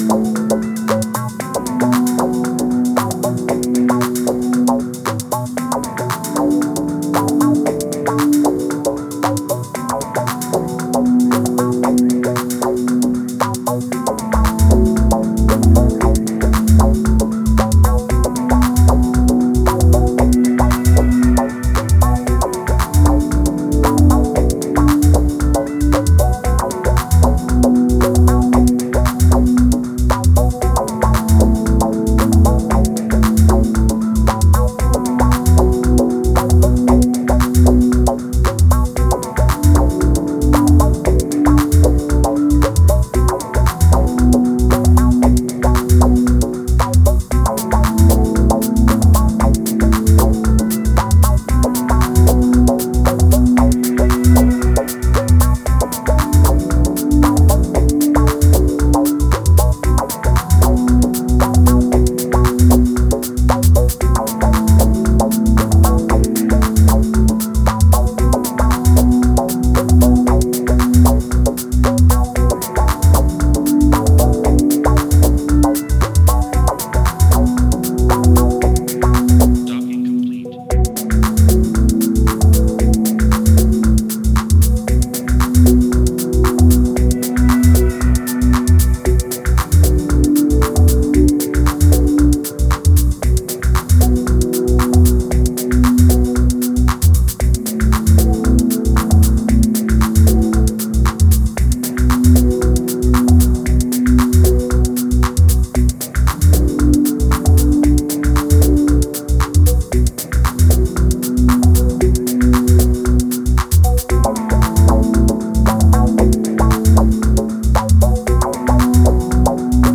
one of the most creative Techno producers in recent years